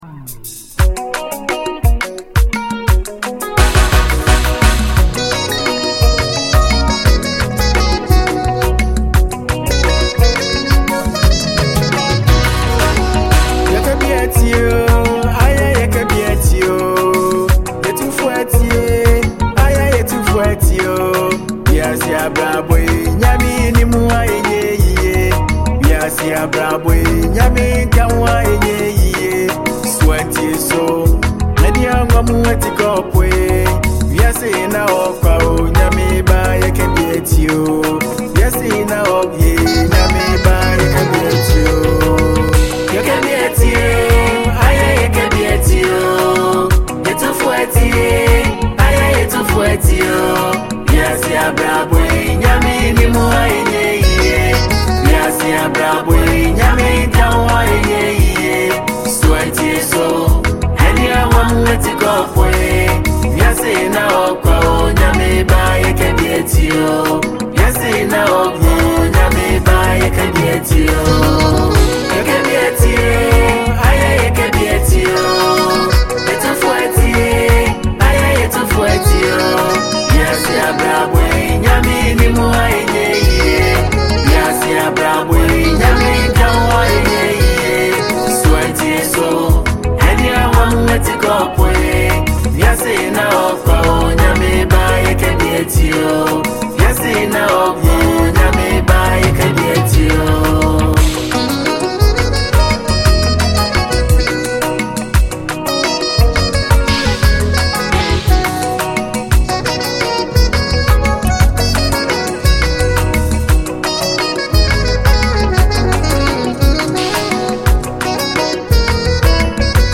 a free mp3 download gospel tune for fans and music lovers
has a cool tempo